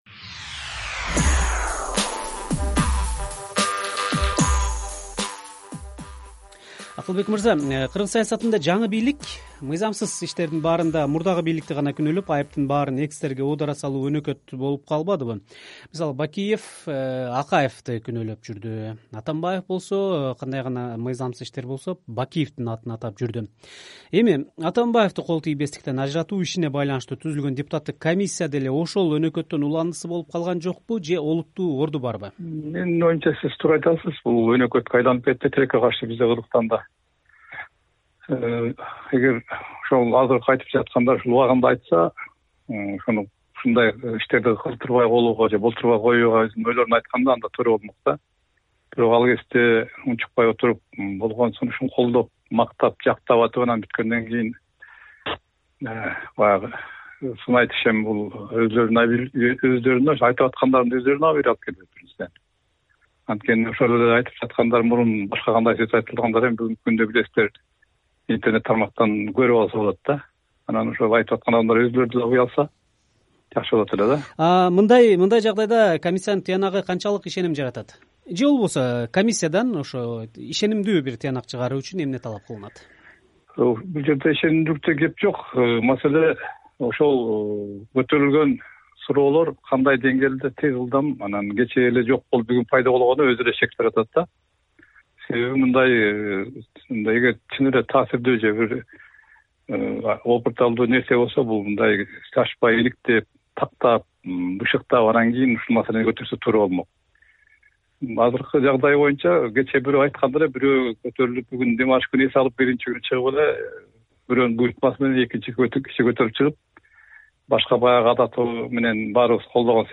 «Азаттык» радиосунун эфиринде сүйлөп жатып